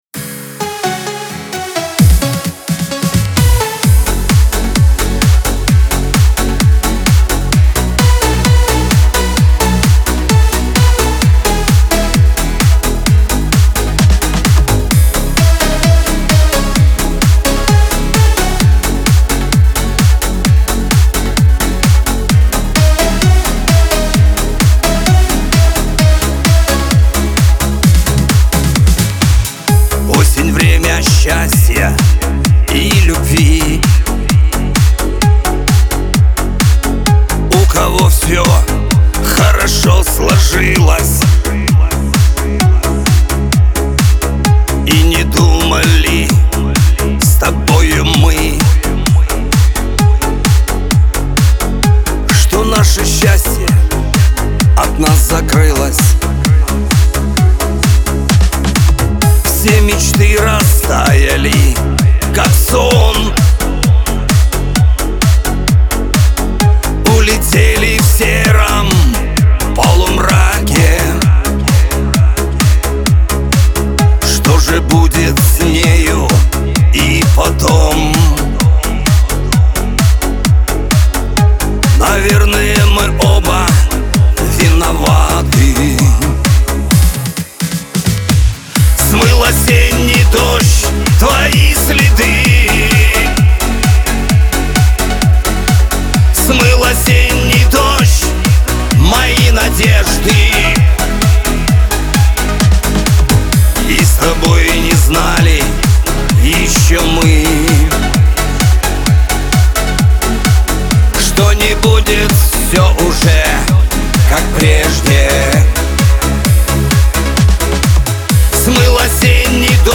Шансон , pop
грусть